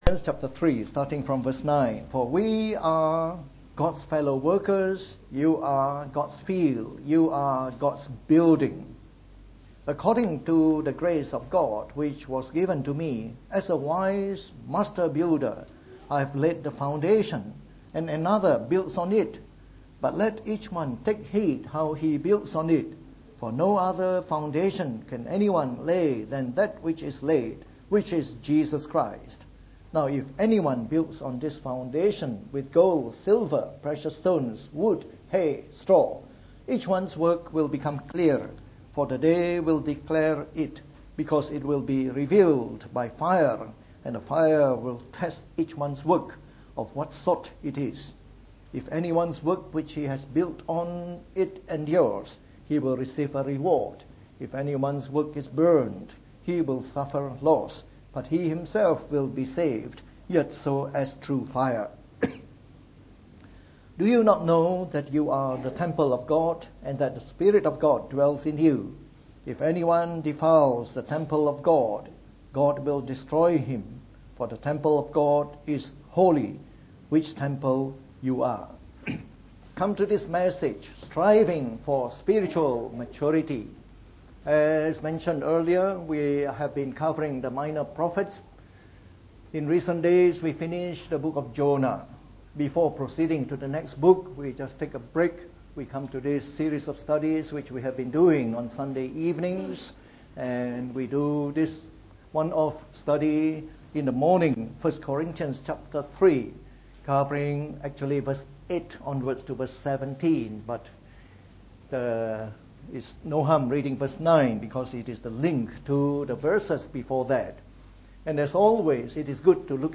From our series on 1 Corinthians delivered in the Morning Service.